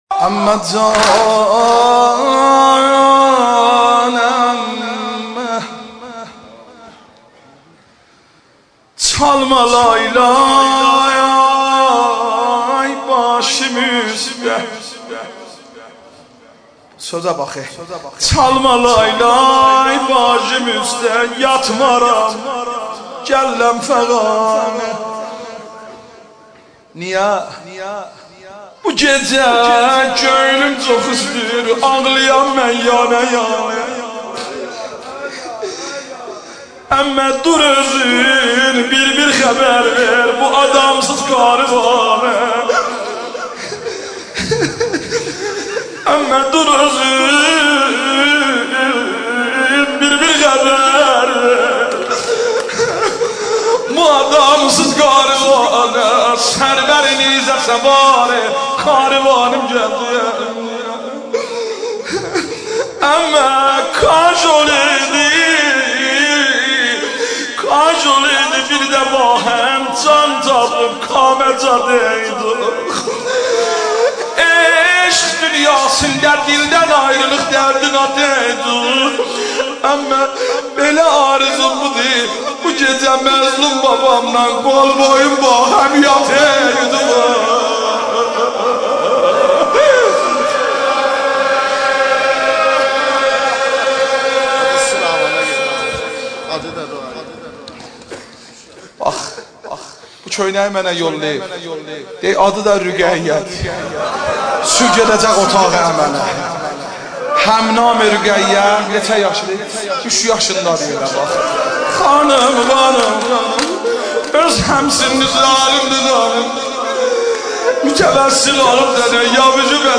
مجلس روضه نوحه ترکی